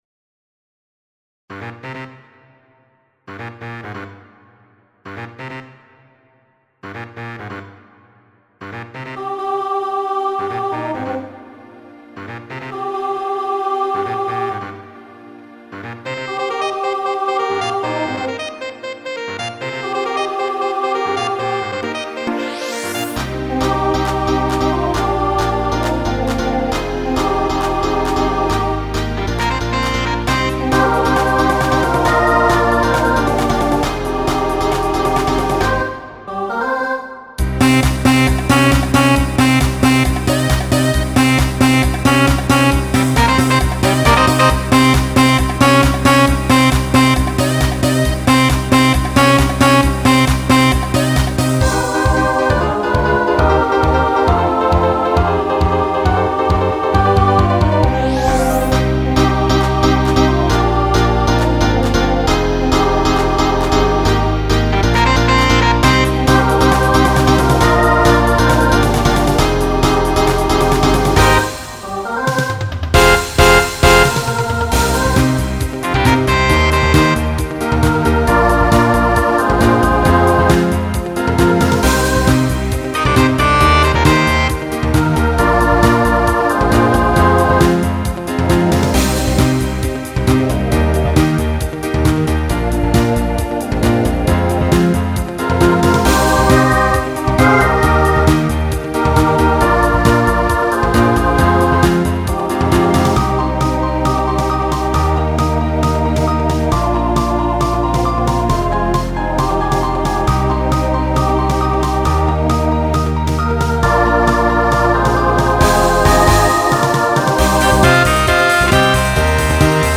Genre Pop/Dance
Opener Voicing SATB